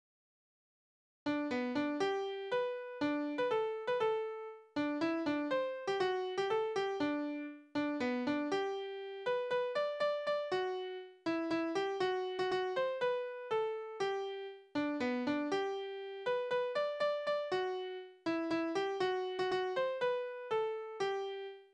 Heimatlieder:
Tonart: G-Dur
Tonumfang: große Dezime
Besetzung: vokal
Anmerkung: Vortragsbezeichnung: Ziemlich langsam